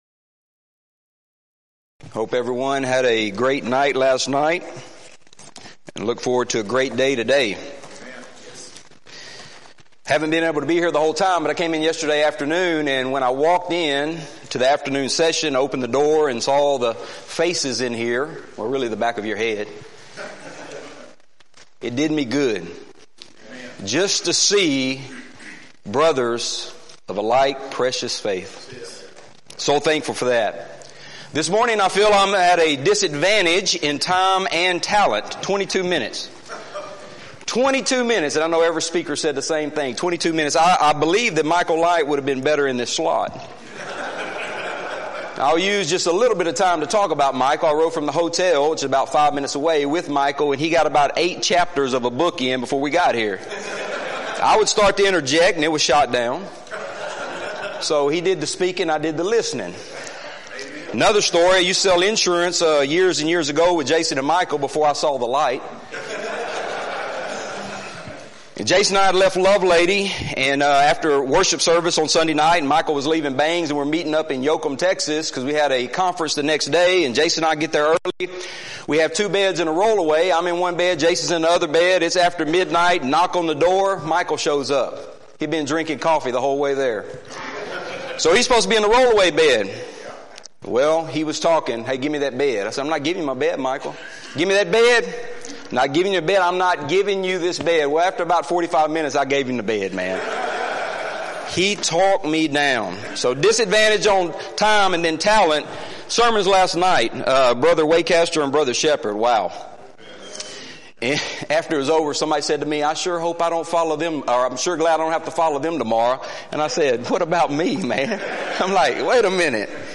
Event: 6th Annual BCS Men's Development Conference
lecture